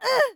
damage_4.wav